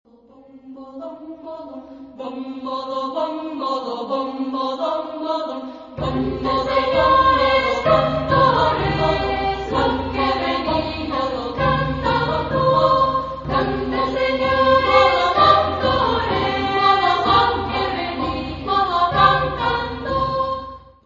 Genre-Style-Form: Popular
Mood of the piece: joyous
Type of Choir: SA  (2 children voices )
Tonality: C minor